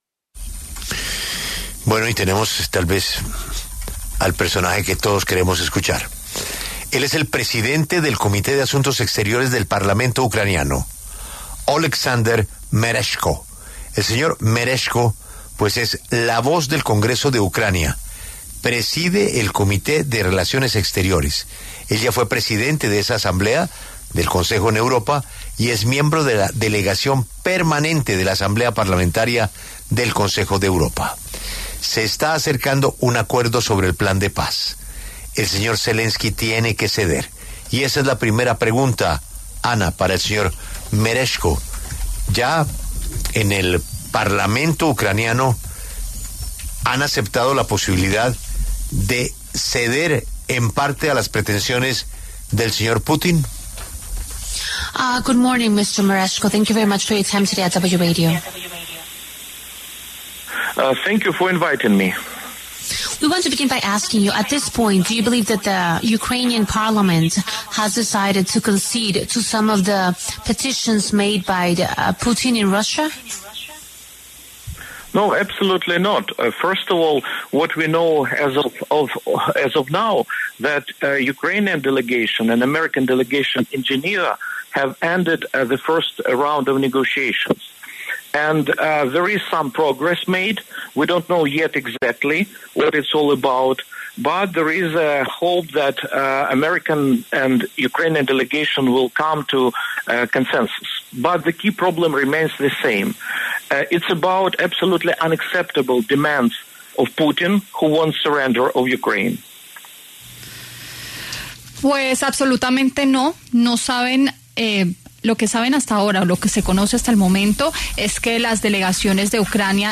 Oleksandr Merezhko, presidente del Comité de Asuntos Exteriores del Parlamento ucraniano, habla en La W sobre las negociaciones de paz con Estados Unidos que iniciaron en Ginebra.